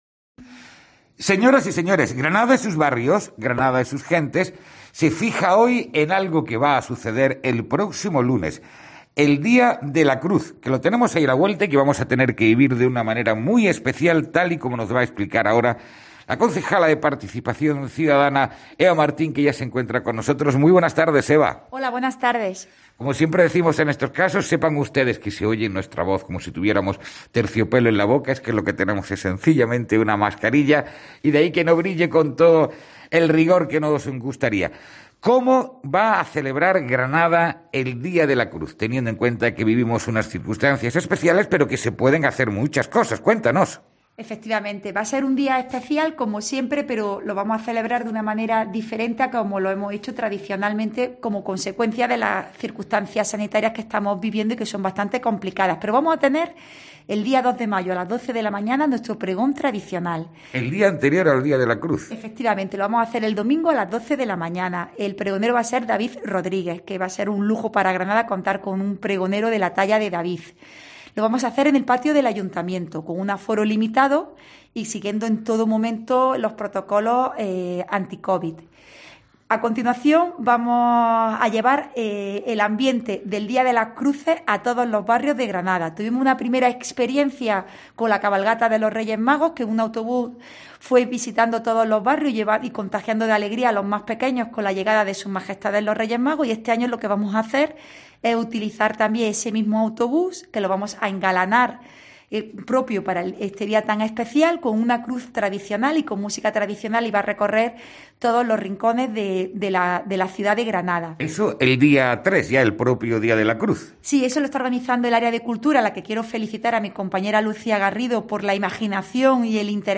AUDIO: GRANADA ES SUS BARRIOS/ Entrevista a la concejala Eva Martín con motivo del Día de la Cruz